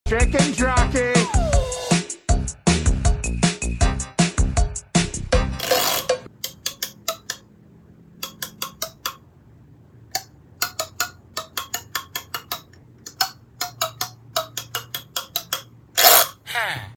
3D Printed Musical Fidget Sound Effects Free Download